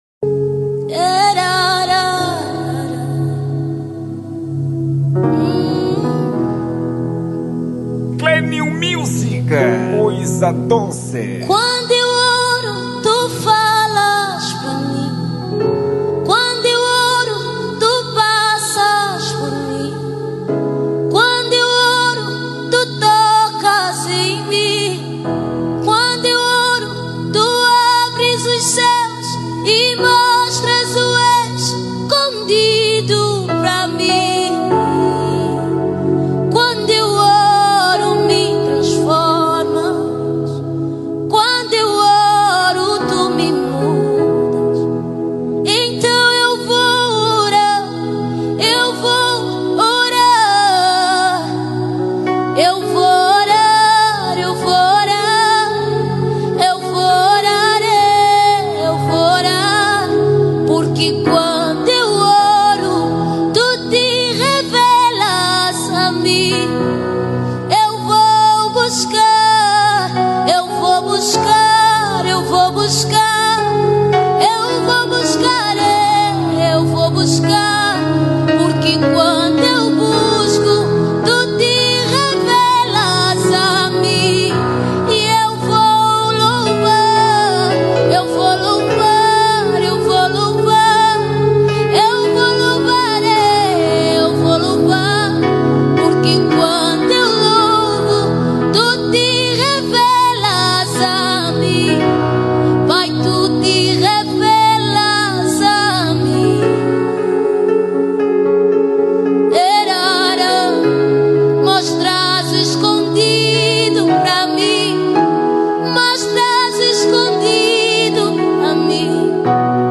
Género: Gospel